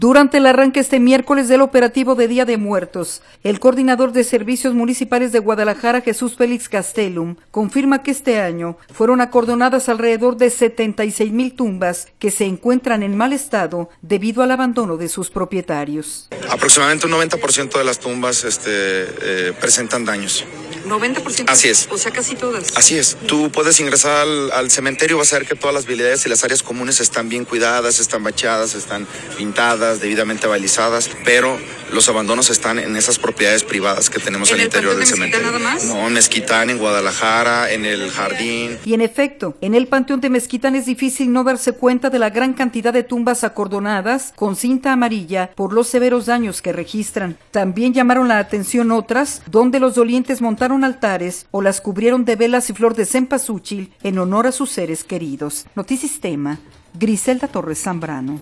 Durante el arranque este miércoles del operativo de Día de Muertos, el coordinador de Servicios Municipales de Guadalajara, Jesús Felix Gastélum, confirma que este año fueron acordonadas alrededor de 76 mil tumbas que se encuentran en mal estado, debido al abandono de sus propietarios.